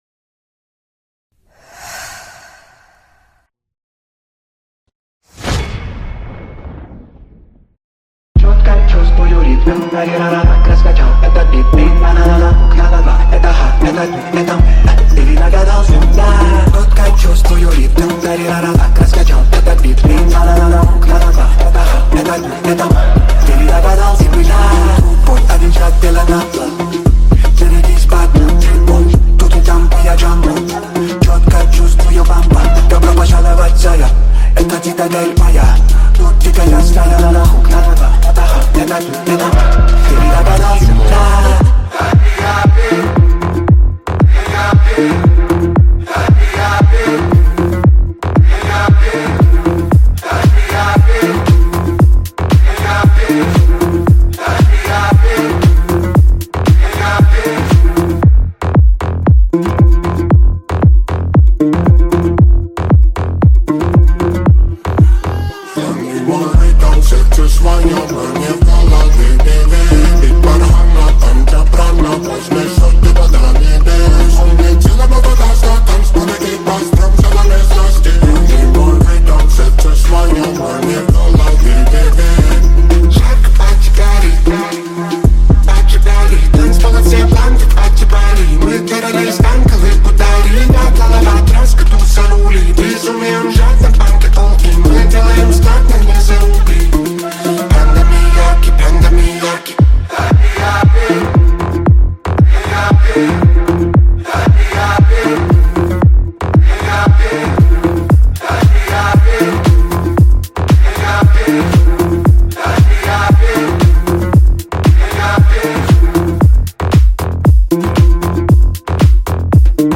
• Категория: Новые ремиксы